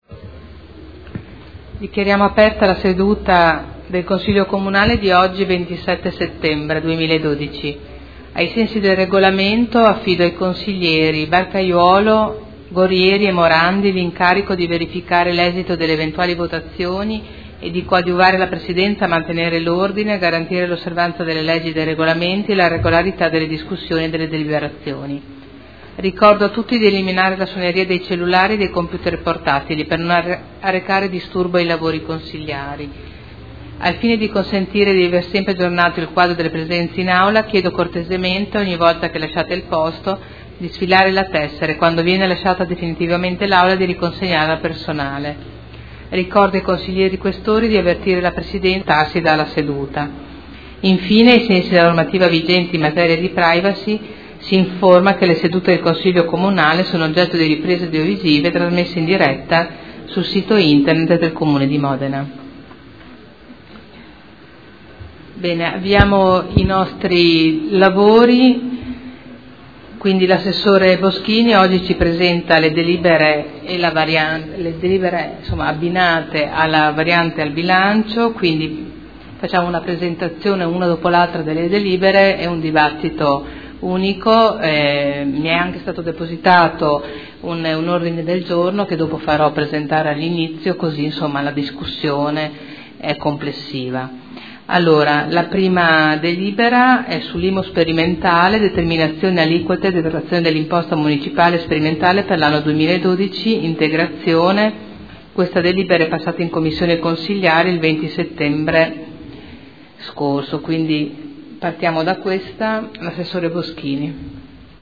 Seduta del 27/09/2012. Apertura lavori